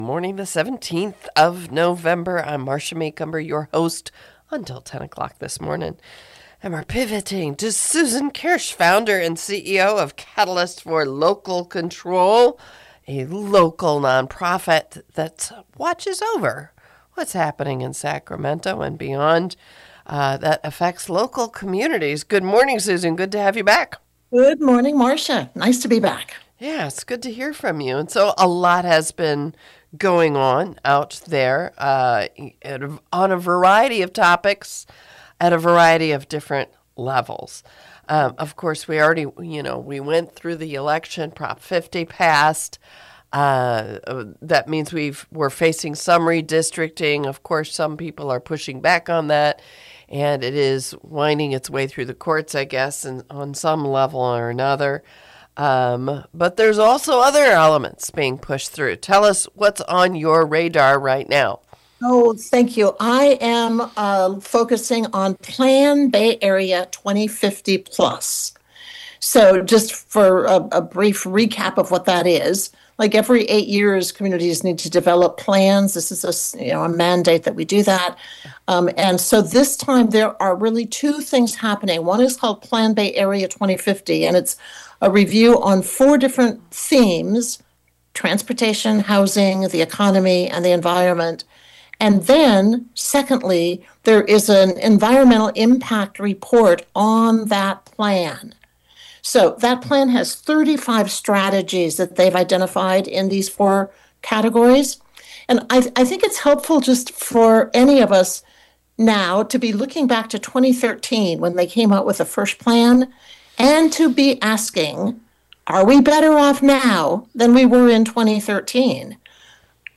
Here is the interview for November 17, 2025